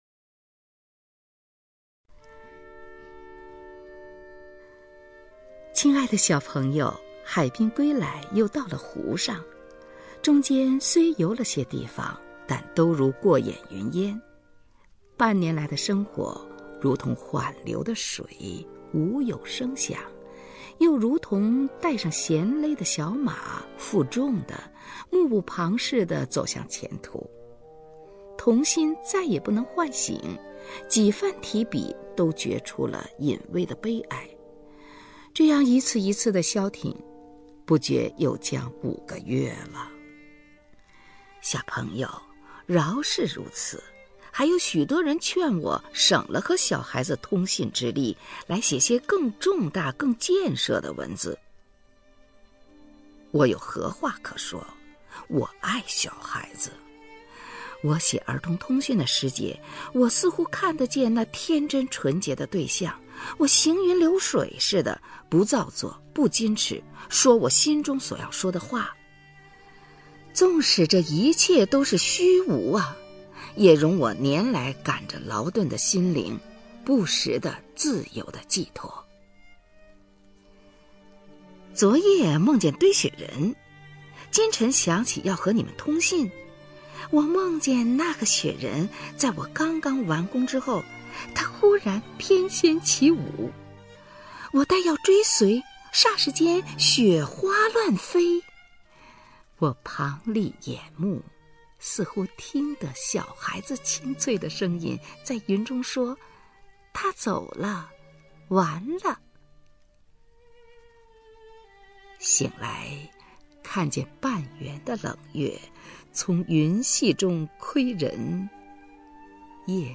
首页 视听 名家朗诵欣赏 虹云
虹云朗诵：《寄小读者（通讯二十五）》(冰心)